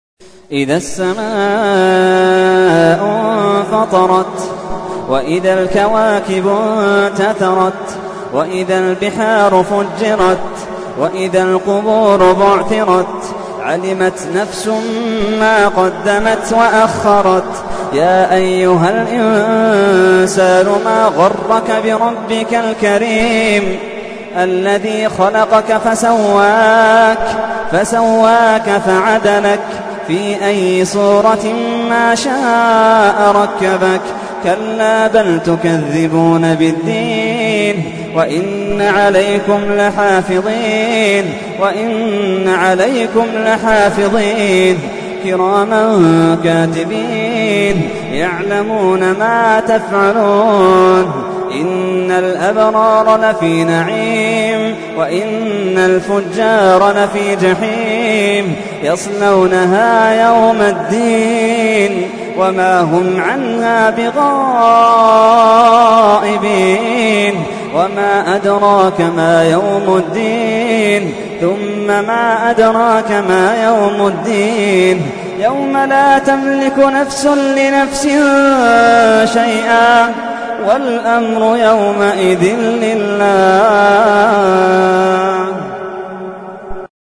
تحميل : 82. سورة الانفطار / القارئ محمد اللحيدان / القرآن الكريم / موقع يا حسين